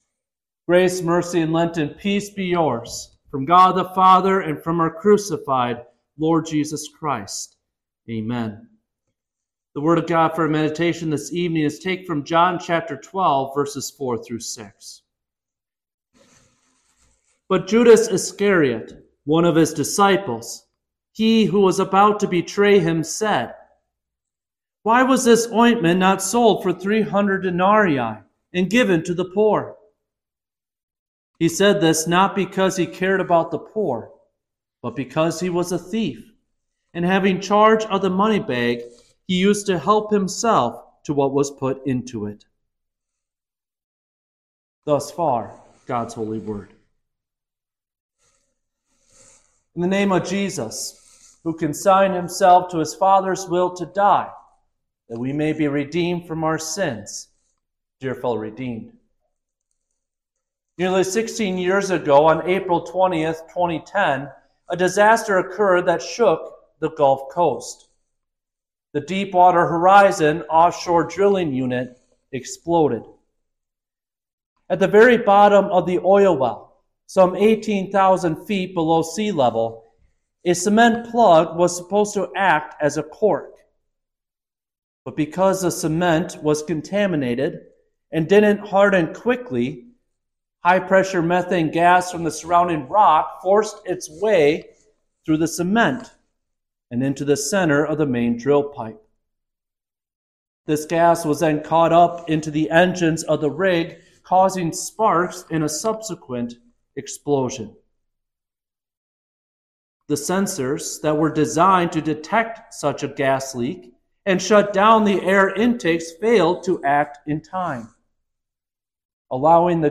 Sixth-Midweek-Lenten-Service.mp3